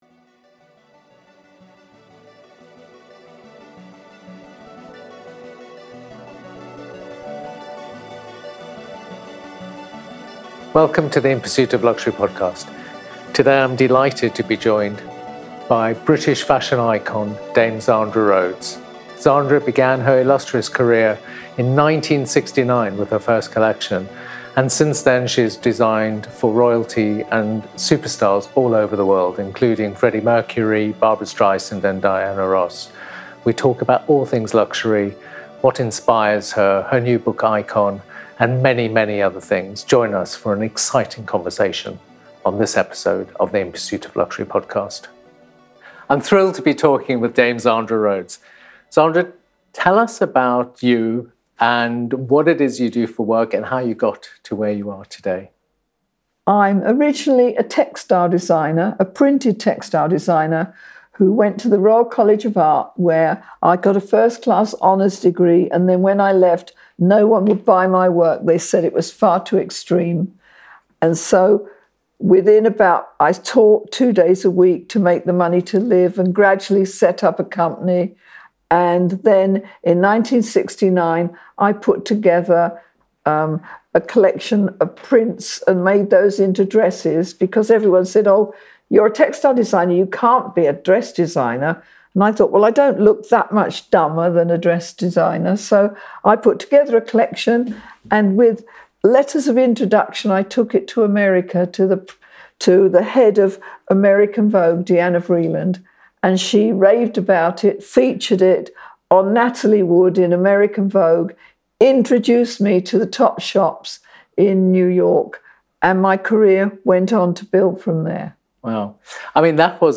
In conversation with Zandra Rhodes